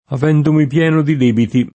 pienare v.; pieno [pL$no] — voce pop., anche tosc., per «empire (completamente)» — part. pass., oltre che pienato, anche, con suffisso zero, pieno [pL$no]: avendomi pieno di debiti [